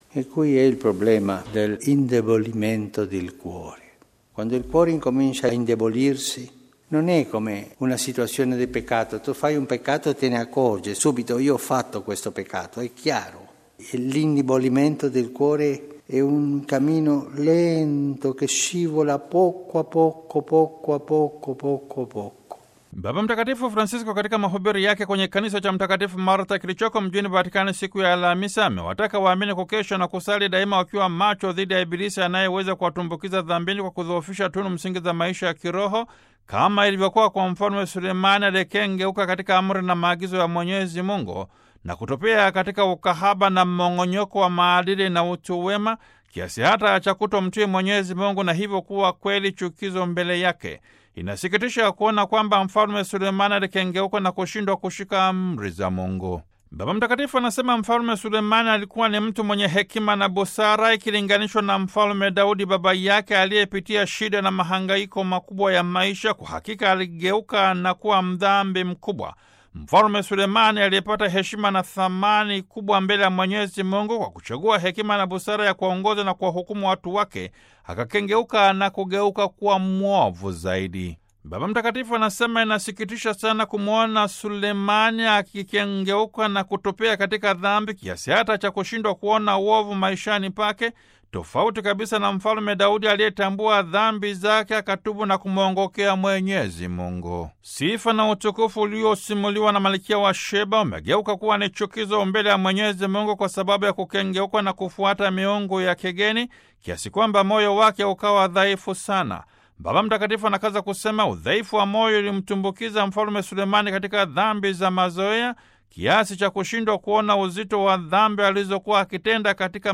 Baba Mtakatifu Francisko katika mahubiri yake kwenye Kikanisa cha Mtakatifu Martha, kilichoko mjini Vatican, Alhamisi, tarehe 8 Februari 2018 amewataka waamini kukesha na kusali, daima wakiwa macho dhidi ya Ibilisi anayeweza kuwatumbukiza dhambini, kwa kudhoofisha tunu msingi za maisha ya kiroho kama ilivyokuwa kwa Mfalme Sulemani aliyekengeuka katika Amri na Maagizo ya Mwenyezi Mungu na kutopotea katika ukahaba na mmong’onyoko wa maadili na utu wema, kiasi hata cha kutomtii Mungu na hivyo kuwa kweli chukizo mbele yake!